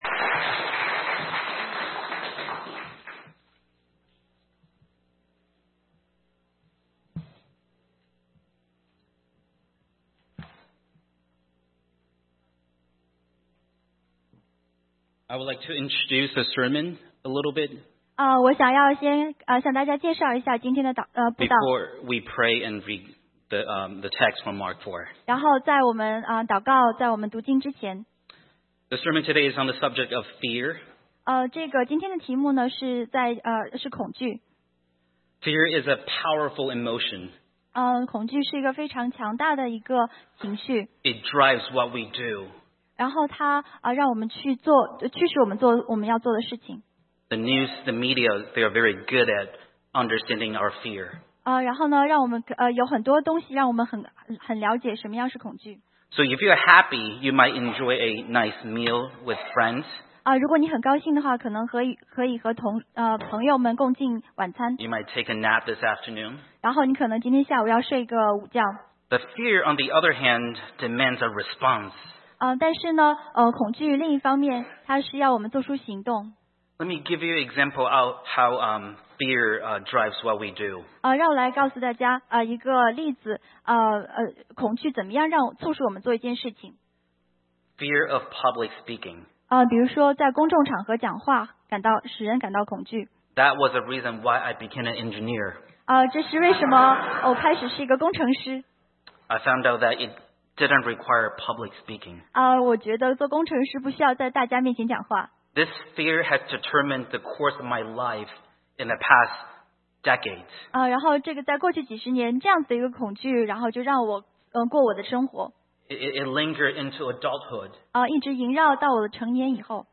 Mandarin Sermons – Page 41 – 安城华人基督教会